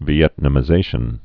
(vē-ĕtnə-mĭ-zāshən, vyĕt-, vēĭt-)